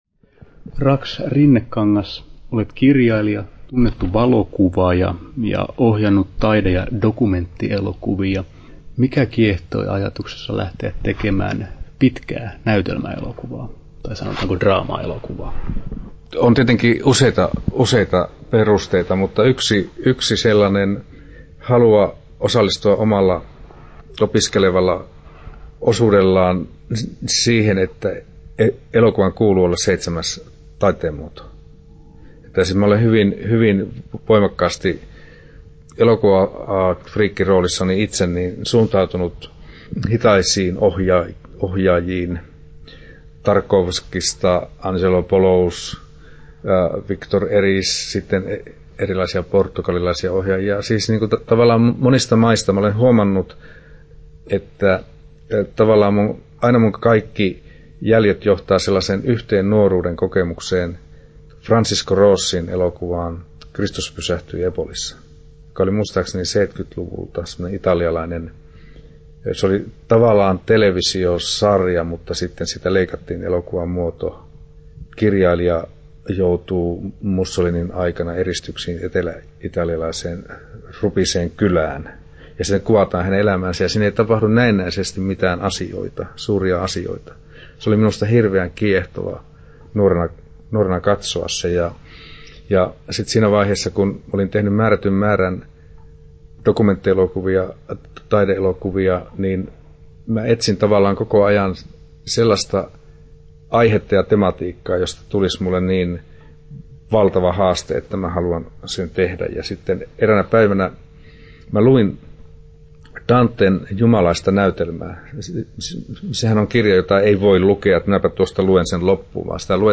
20'02" Tallennettu: 21.9.2011, Turku Toimittaja